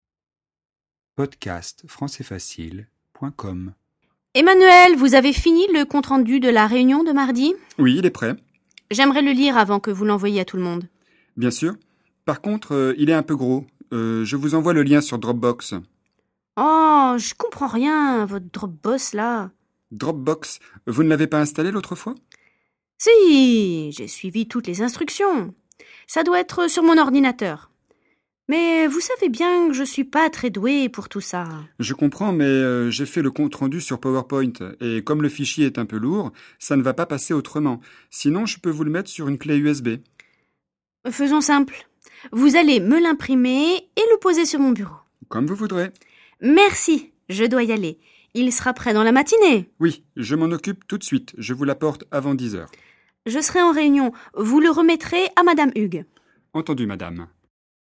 Dialogue FLE, niveau intermédiaire (A2) sur un thème professionnel